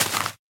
Minecraft / dig / grass1.ogg
grass1.ogg